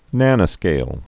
(nănə-skāl)